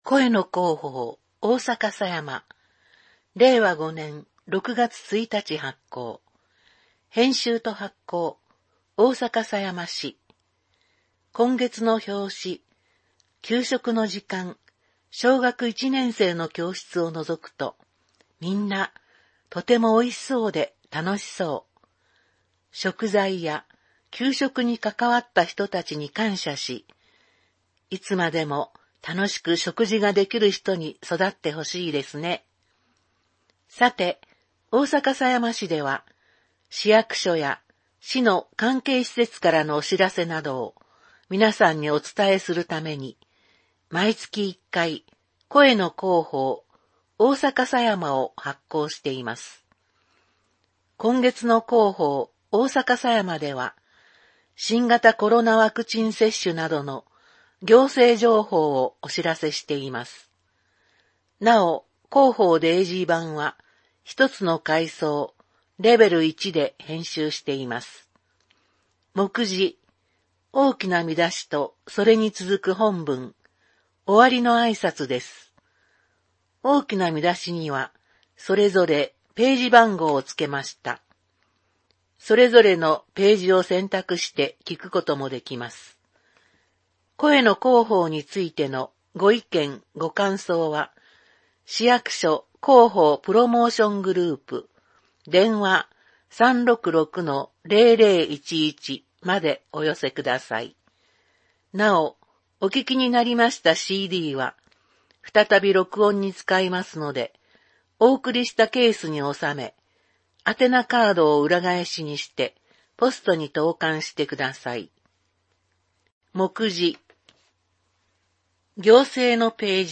大阪狭山市では、視覚に障がいのある人を対象にした音声の広報誌「声の広報」を発行しています。これは、「広報おおさかさやま」の内容をCDに収録したものです。